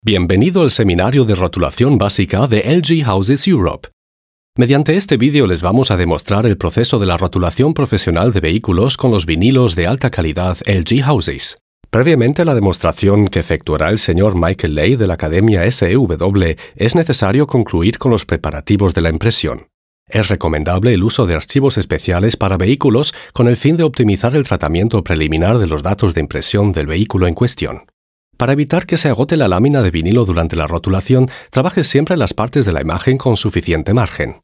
Volle, warme, präsente, moderative und rassige spanische Voice-Over-Stimme
kastilisch
Sprechprobe: eLearning (Muttersprache):
Experienced spanish Voice-Over Actor; Full, warm, prominent voice